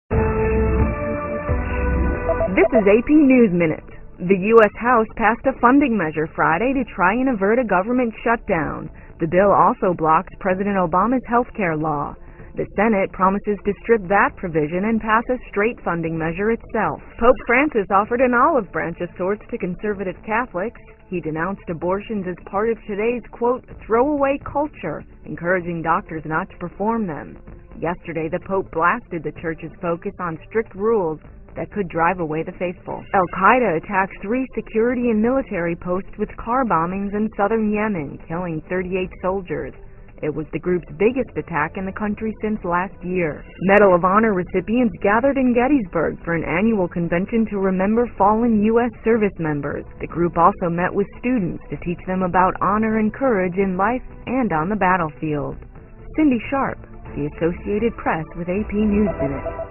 在线英语听力室美联社新闻一分钟 AP 2013-09-26的听力文件下载,美联社新闻一分钟2013,英语听力,英语新闻,英语MP3 由美联社编辑的一分钟国际电视新闻，报道每天发生的重大国际事件。电视新闻片长一分钟，一般包括五个小段，简明扼要，语言规范，便于大家快速了解世界大事。